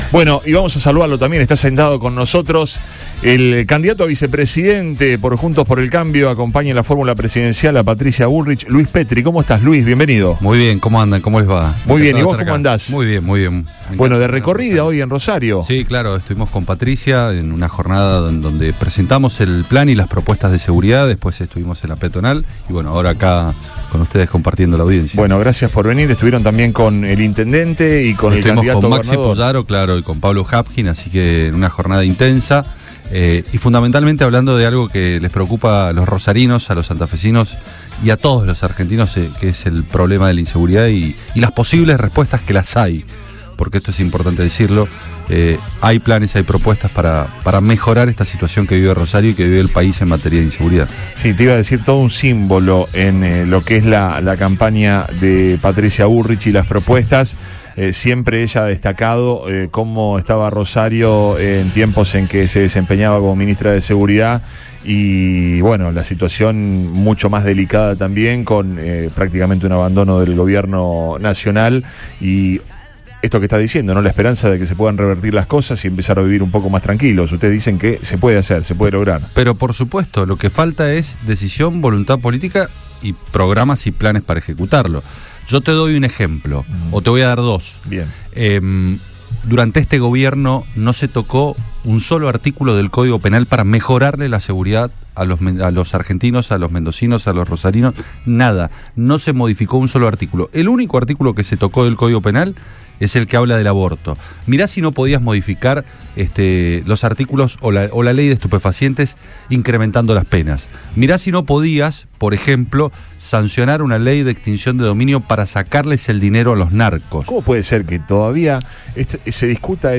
Luis Petri, precandidato a vicepresidente por Juntos por el Cambio por el espacio de Patricia Bullrich, remarcó en la visita a los estudios de Radio Boing 97.3…